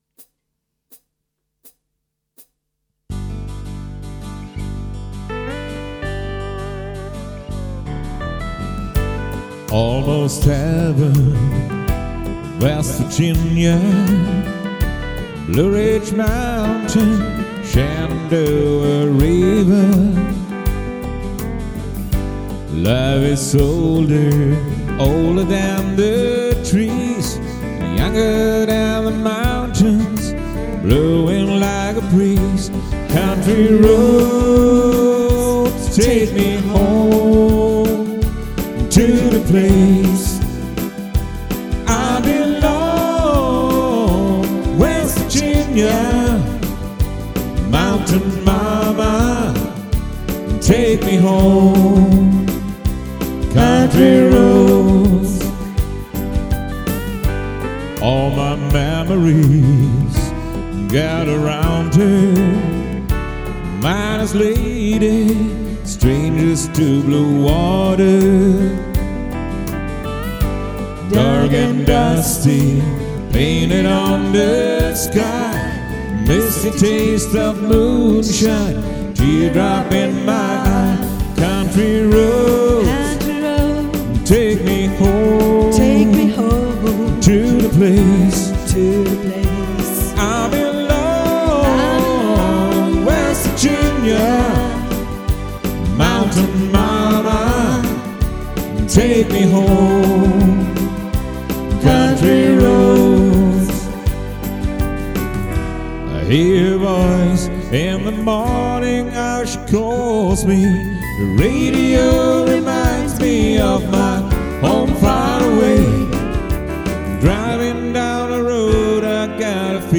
Der Sänger und Gitarrist
Die Keyboarderin und Sängerin
• Country